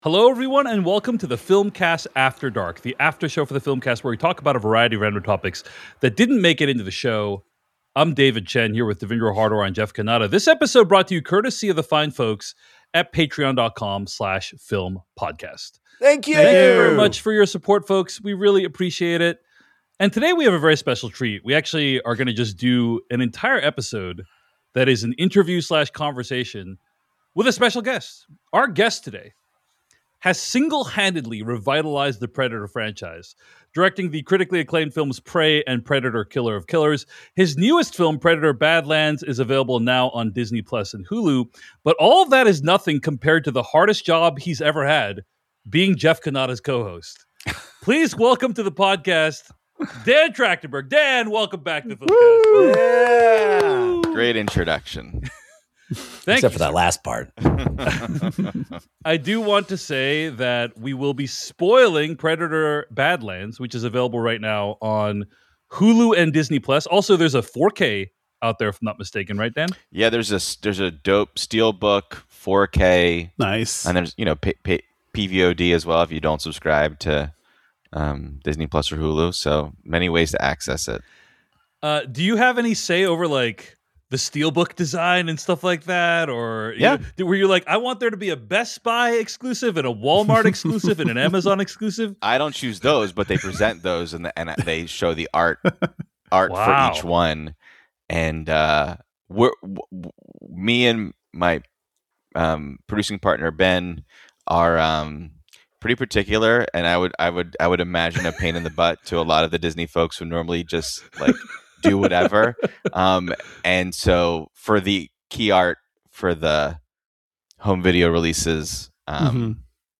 The Filmcast / After Dark Interview: How Dan Trachtenberg Made 'Predator: Badlands'